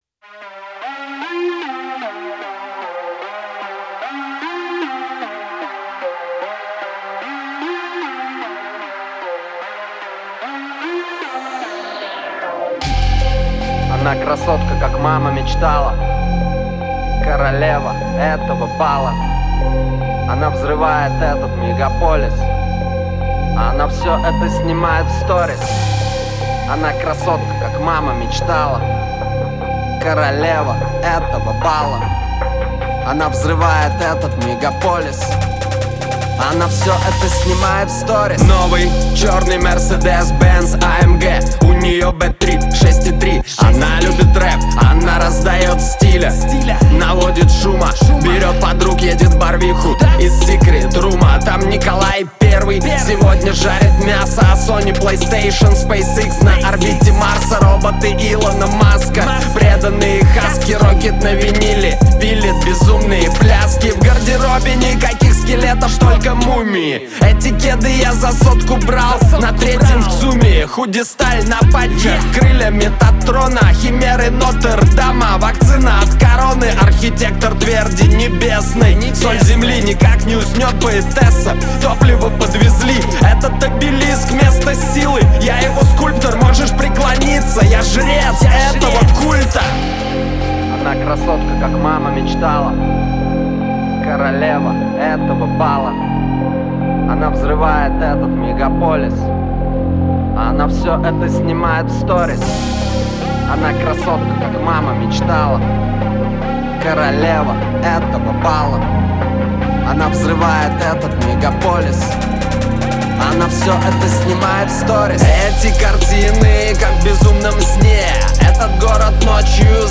это атмосферный трек в жанре хип-хоп